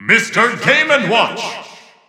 The announcer saying Mr. Game & Watch's name in English and Japanese releases of Super Smash Bros. 4 and Super Smash Bros. Ultimate.
Mr._Game_&_Watch_English_Announcer_SSB4-SSBU.wav